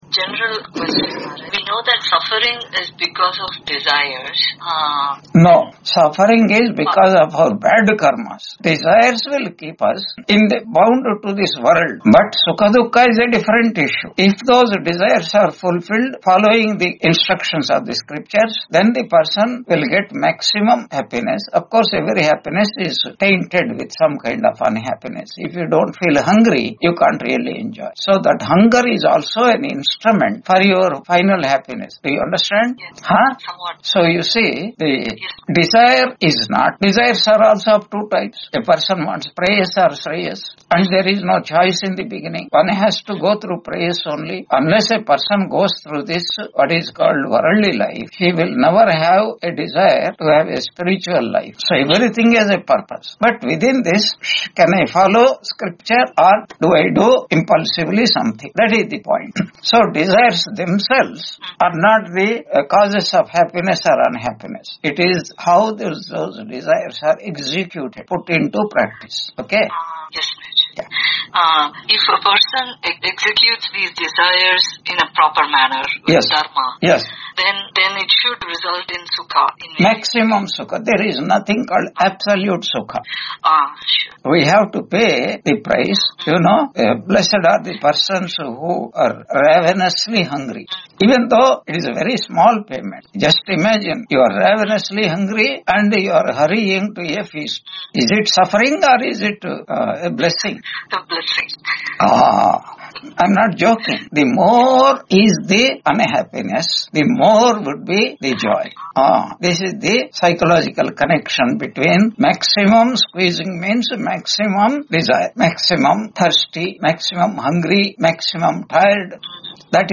Chandogya Upanishad 4.15 Lecture 146 on 12 October 2025 Q&A